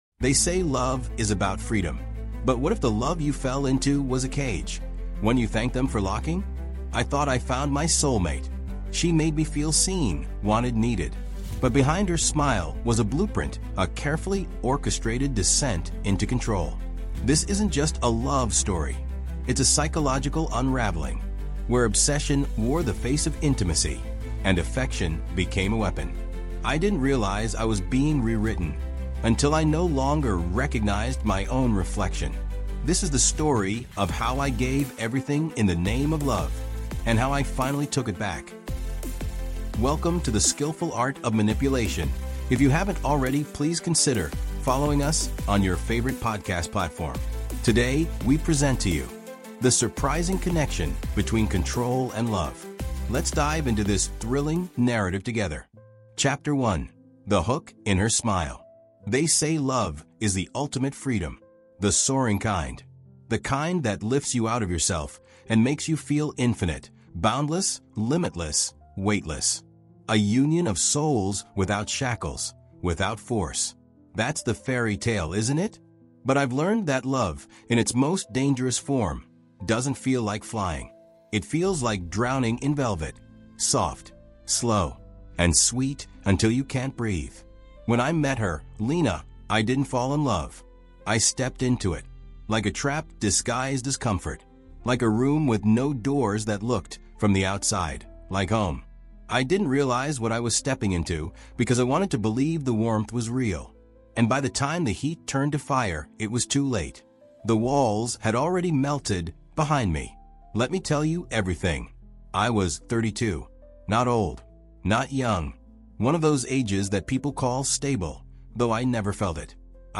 The Surprising Connection Between Control And Love | Audiobook